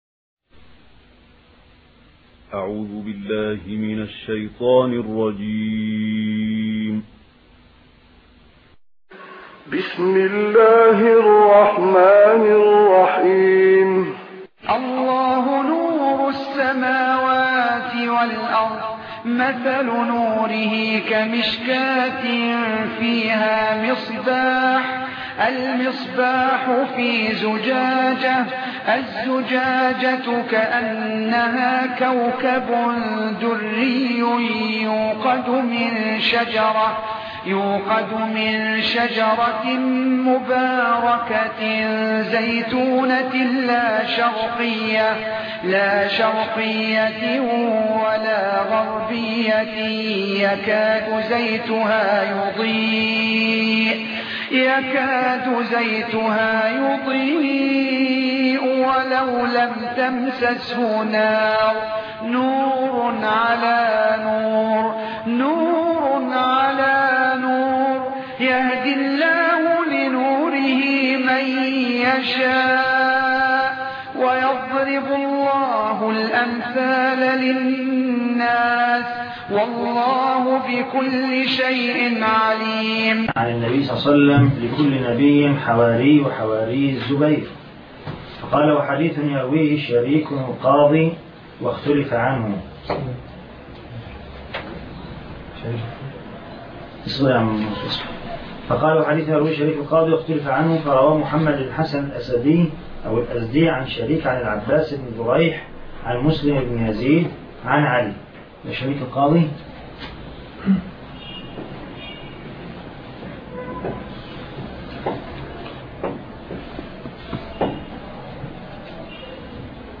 الدرس 12 ( شرح علل ابن رجب